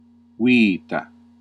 Ääntäminen
Ääntäminen Classical: IPA: /ˈwiː.ta/ Haettu sana löytyi näillä lähdekielillä: latina Käännös Konteksti Ääninäyte Substantiivit 1. life biologia, filosofia US UK 2. being UK US Suku: f .